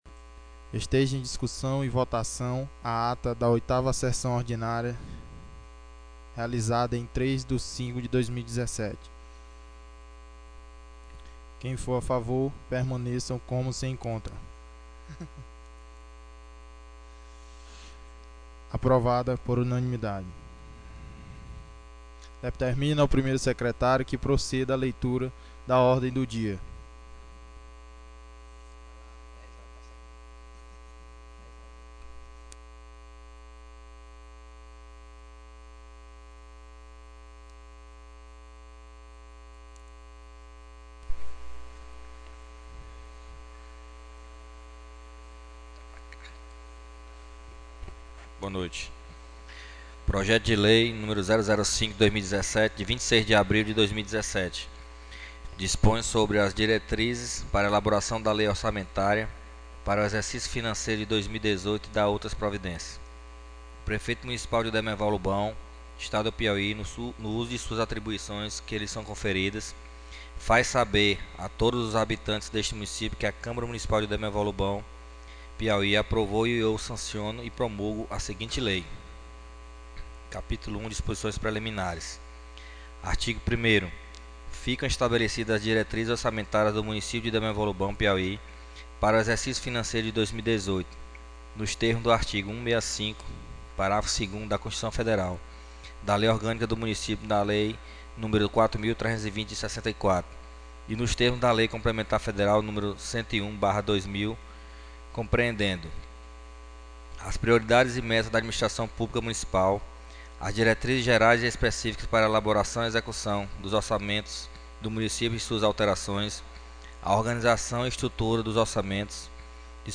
9ª SESSÃO ORDINÁRIA 10/05/2017